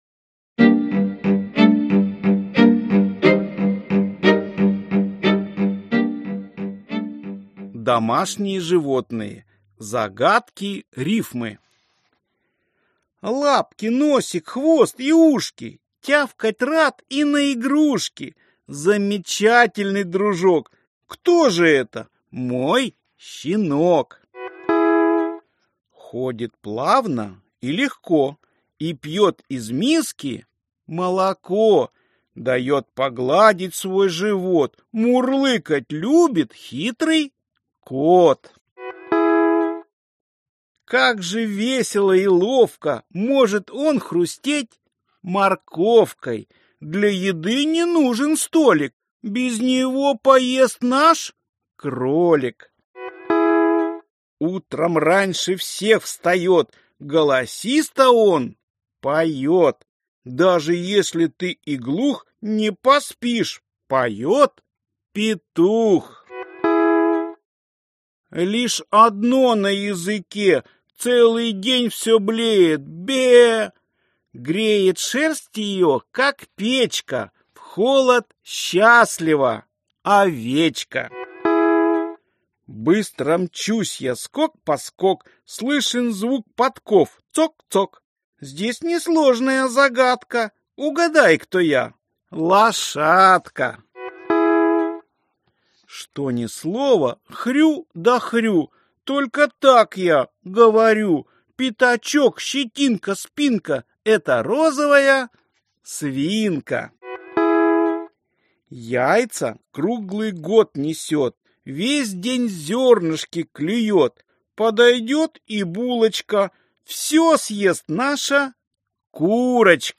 Аудиокнига Загадки-рифмы для малышей | Библиотека аудиокниг